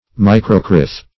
microcrith - definition of microcrith - synonyms, pronunciation, spelling from Free Dictionary
Microcrith \Mi`cro*crith"\, n. [Micro- + crith.]